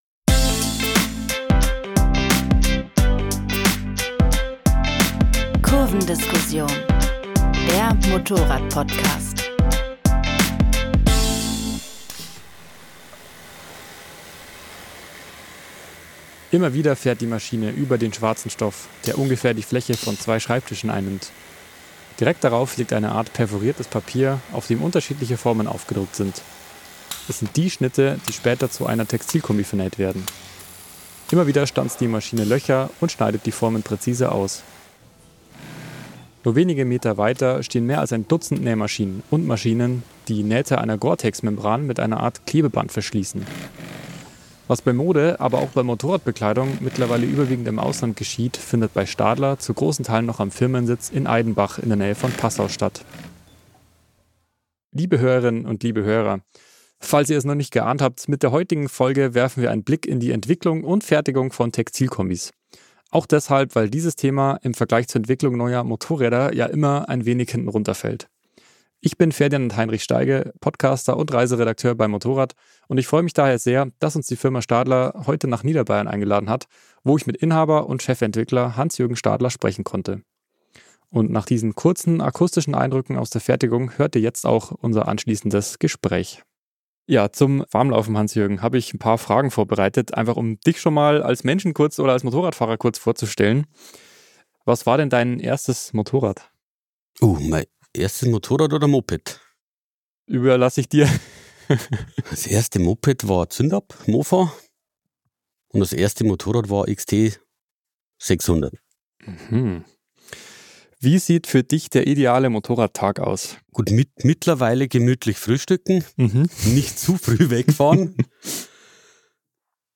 In den Folgen, die meist zwischen 45 und 60 Minuten dauern, gibt es außerdem viele persönliche Eindrücke und die ein oder andere Anekdote aus dem Redaktionsalltag zu hören, die es im Heft nicht zu lesen gibt.